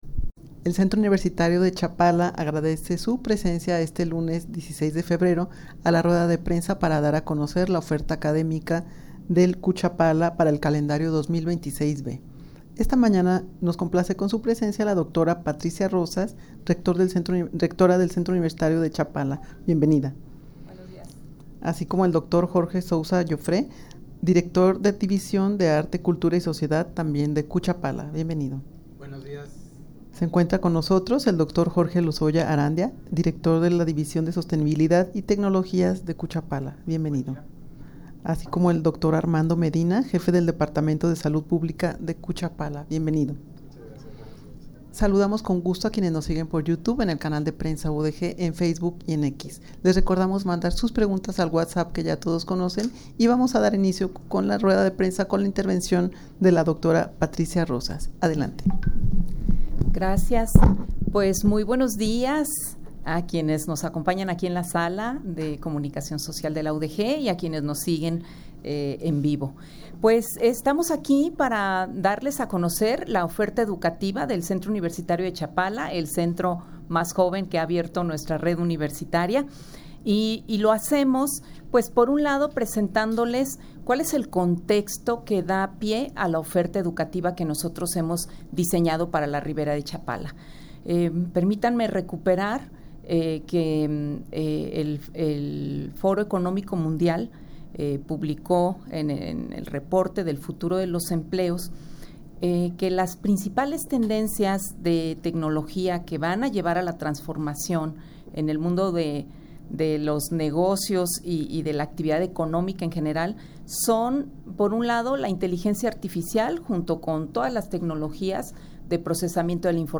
El Centro Universitario de Chapala (CUChapala), de la Universidad de Guadalajara (UdeG), dio a conocer en rueda de prensa los nueve programas de licenciaturas e ingenierías en las áreas de la salud, tecnologías y sostenibilidad, sociales y humanidades, para el calendario 2026-B.
rueda-de-prensa-para-dar-a-conocer-la-oferta-academica-de-cuchapala-para-el-calendario-2026-b.mp3